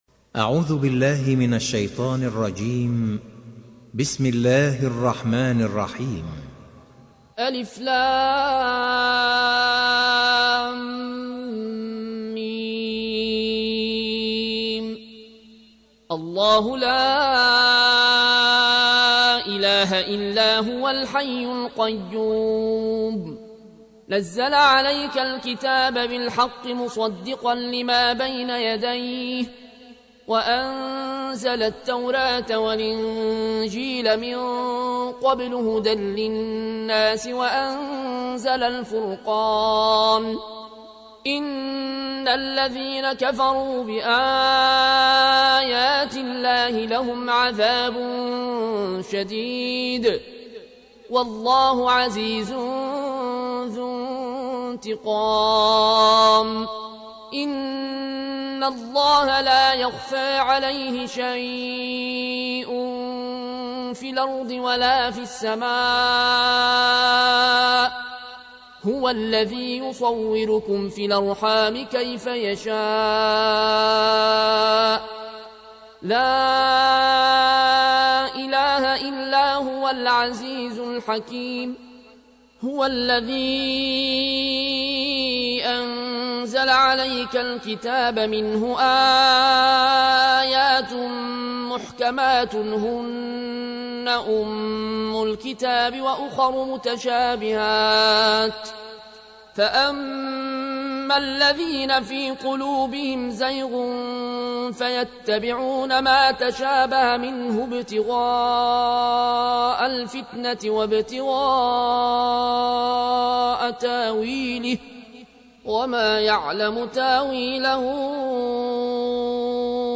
تحميل : 3. سورة آل عمران / القارئ العيون الكوشي / القرآن الكريم / موقع يا حسين